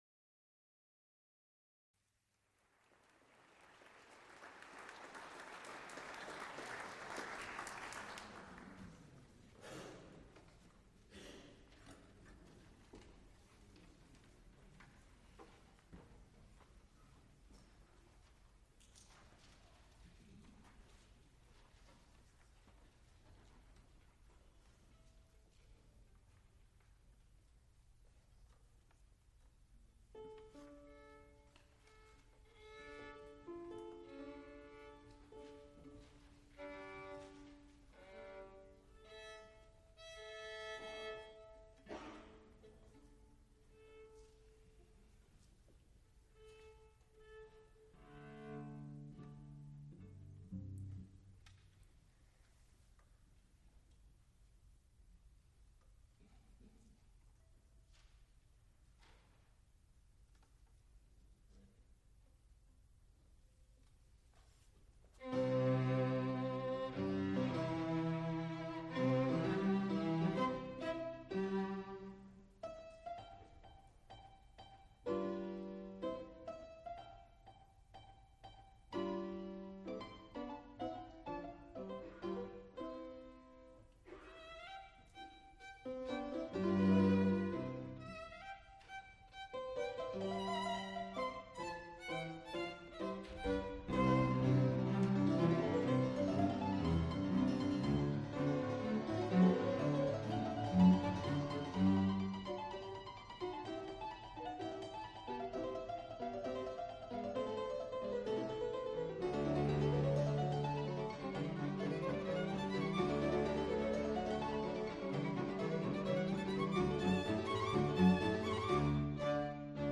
Piano trios
violin
cello
fortepiano., Recorded live May 11, 1982, Frick Fine Arts Auditorium, University of Pittsburgh.
analog, half track, 7 1/2 ips